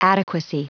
Prononciation du mot adequacy en anglais (fichier audio)